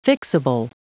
Prononciation du mot fixable en anglais (fichier audio)
Prononciation du mot : fixable